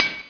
Hit3.ogg